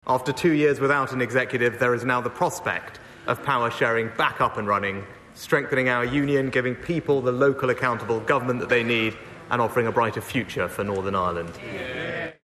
British Prime Minister Rishi Sunak has thanked everyone for their patience: